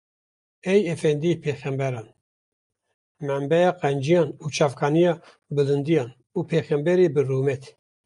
/ruːˈmɛt/